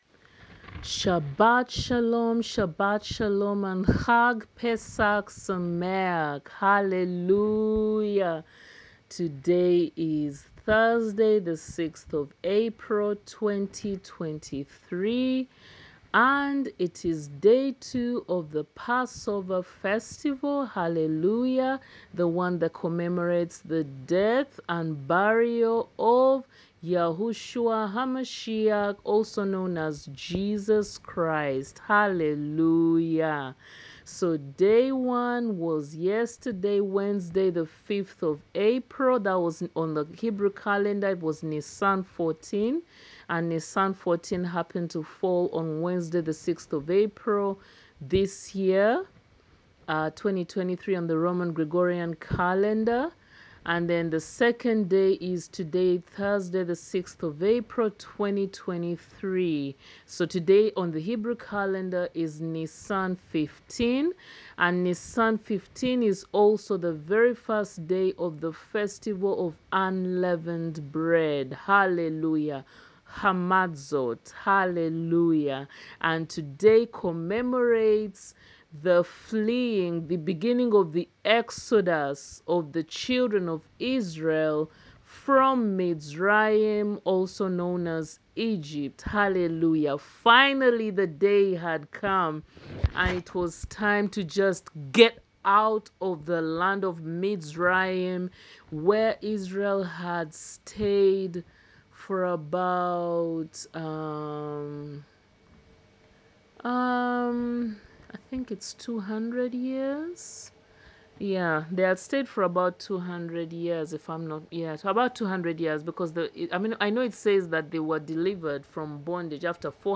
AUDIO Recording 138: PT1 [2023] NISAN 15 [PASSOVER/PESACH Day 2] Prophetic Teaching incl REVELATIONS; etc